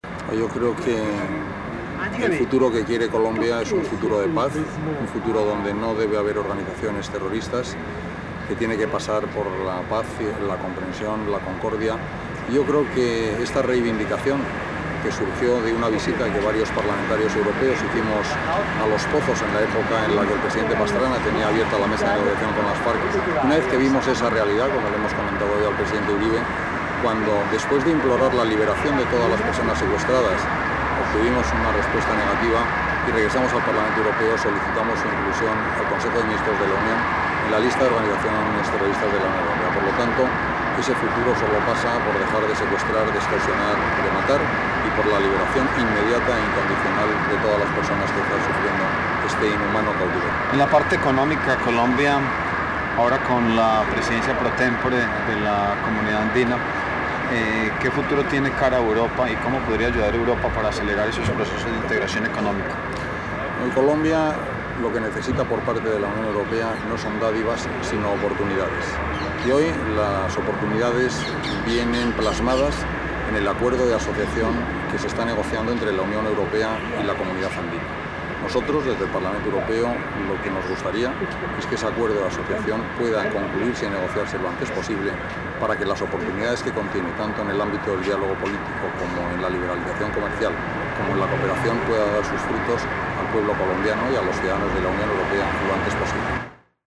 Voz del eurodiputado José Ignacio Salafranca, Presidente del Parlamento Europeo-Latinoamericano